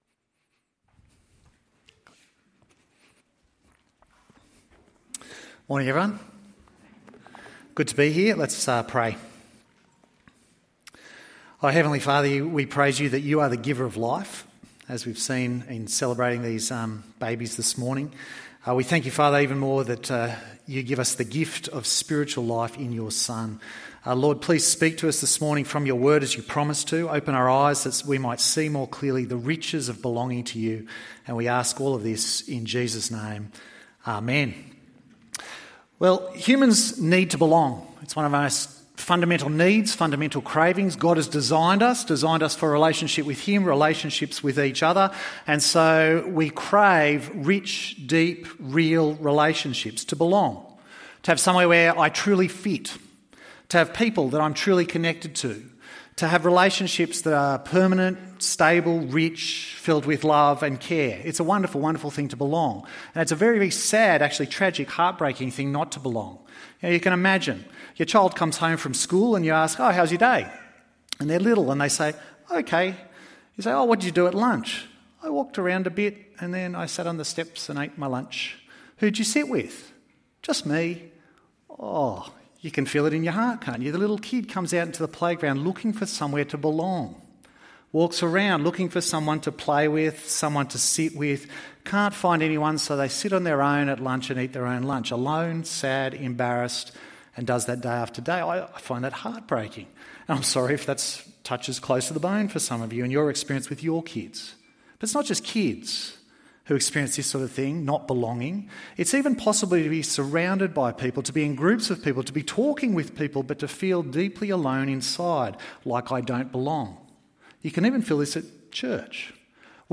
Discernment in the Son ~ EV Church Sermons Podcast